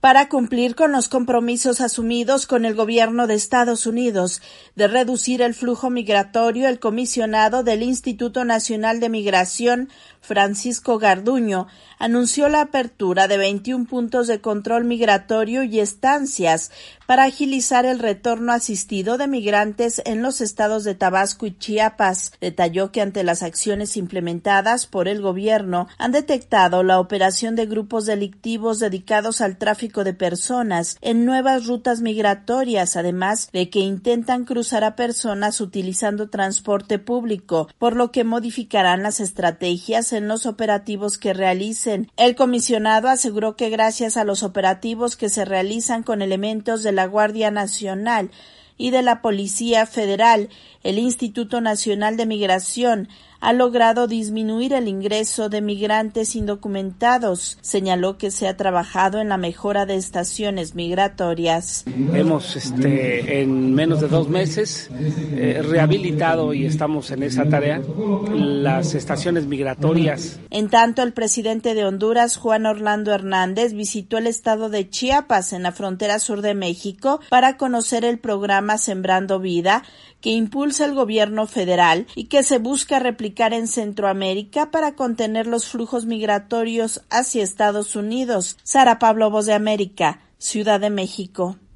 VOA: Informe desde México